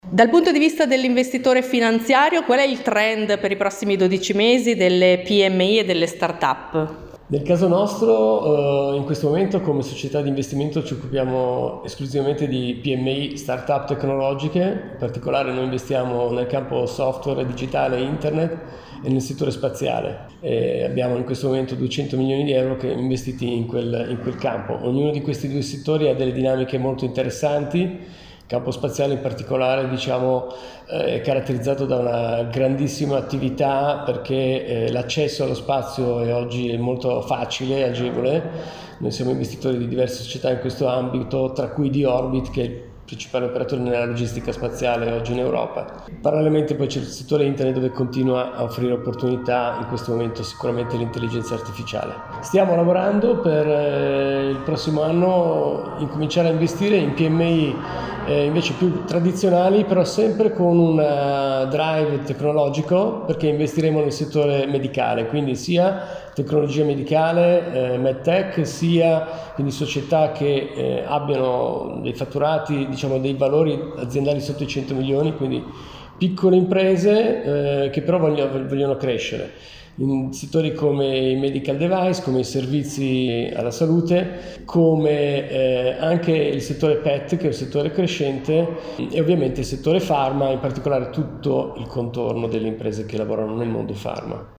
I Portici Hotel – Via Indipendenza, 69 – Bologna
l’intervista